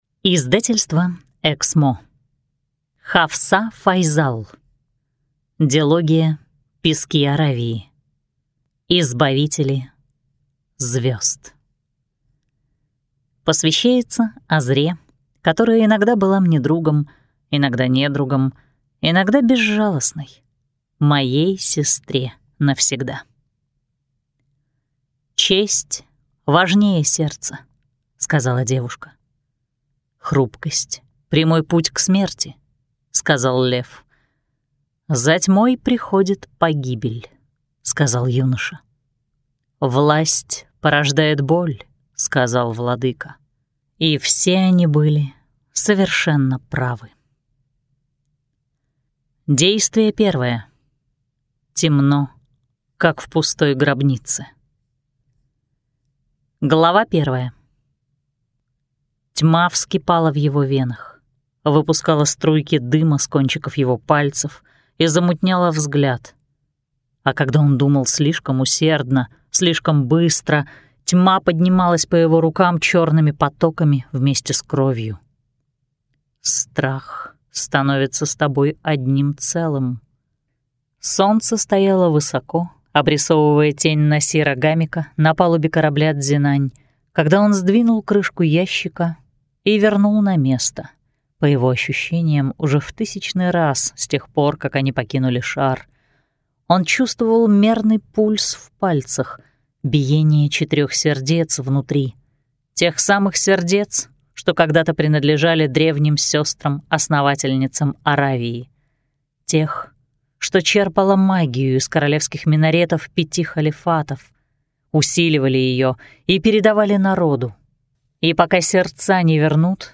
Аудиокнига Избавители звезд | Библиотека аудиокниг